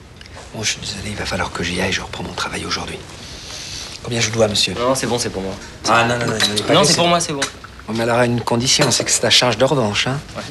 Le cryptage du son canal+ est en fait une inversion (ou retournement) du spectre cr�� par une modulation d'amplitude par un signal sinuso�dal de fr�quence 12800 Hz.